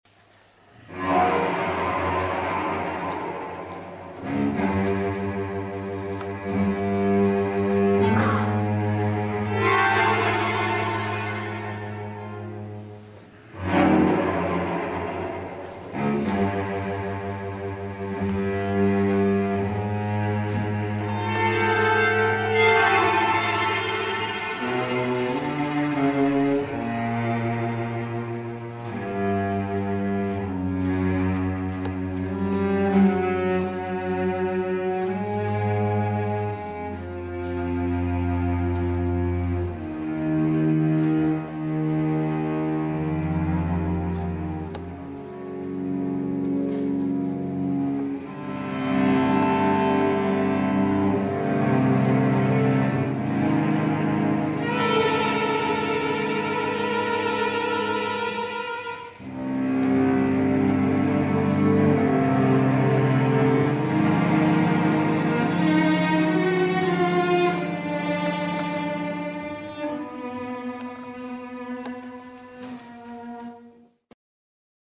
Récit pour 5 Violoncelles